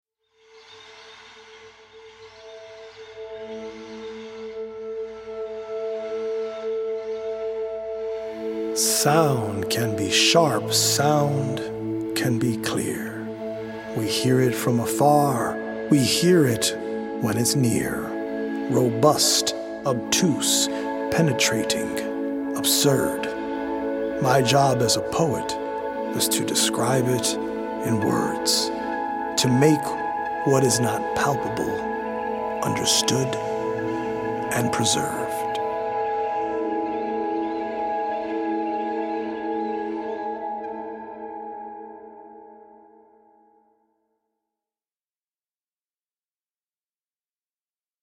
audio-visual poetic journey
healing Solfeggio frequency music
EDM